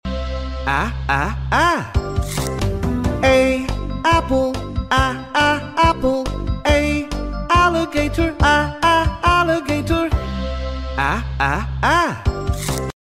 the letter A phonics Sounds, sound effects free download
the letter A phonics Sounds, apple alligator